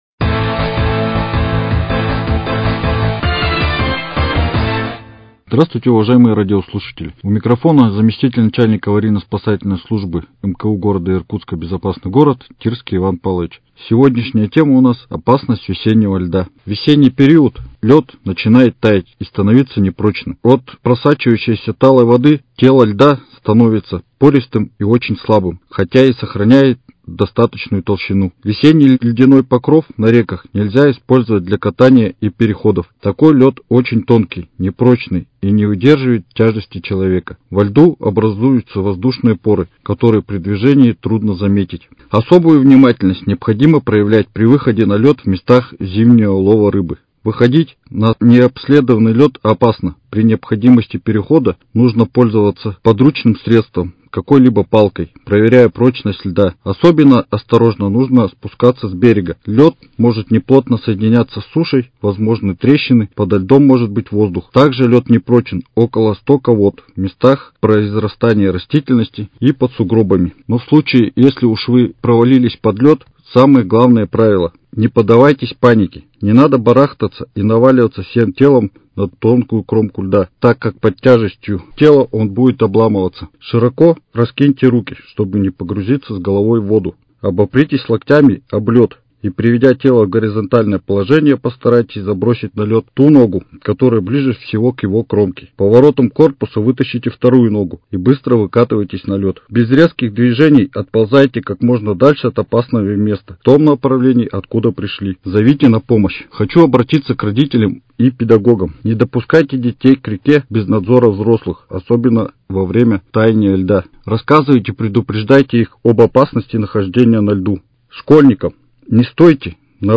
Радиожурнал «Информирует МКУ «Безопасный город»: Правила поведения на весеннем льду 05.04.2022